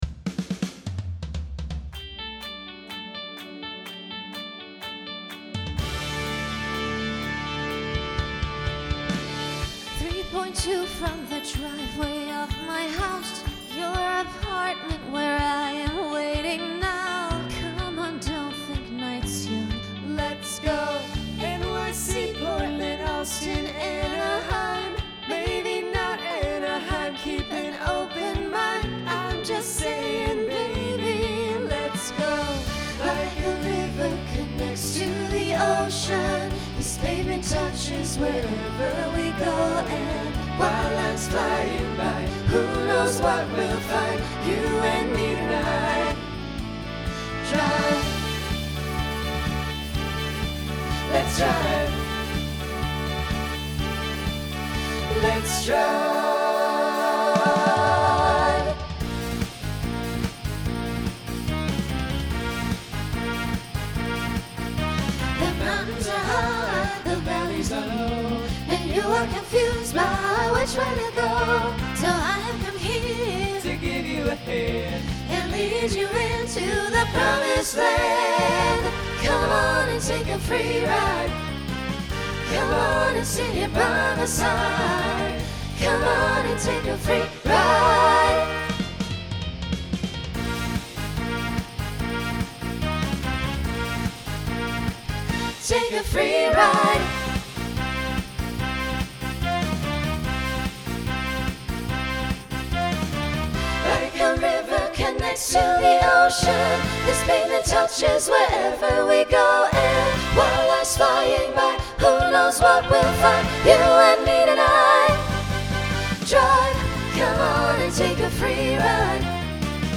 2010s Genre Pop/Dance , Rock
Voicing SATB